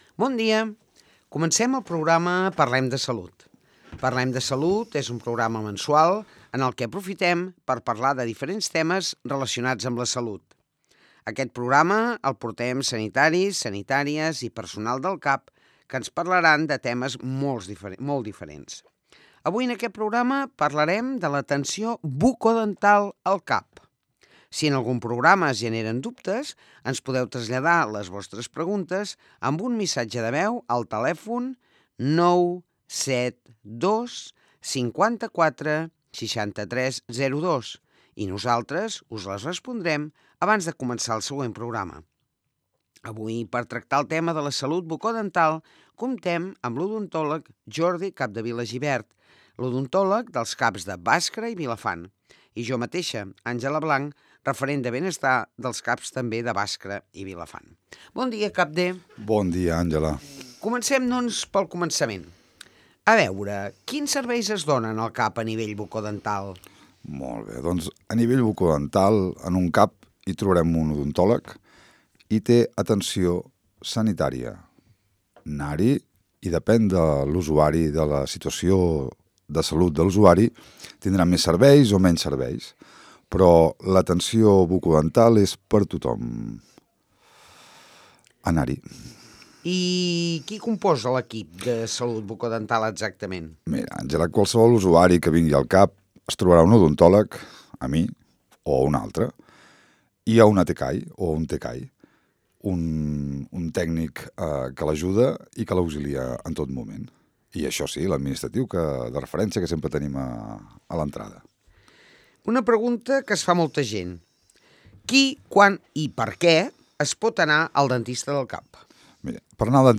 Avui, a Les Veus del Matí, hem estrenat una nova secció de salut amb els i les professionals del CAP de Vilafant, centrada en l’atenció bucodental.
Una conversa informativa i útil per apropar els serveis de salut a la ciutadania.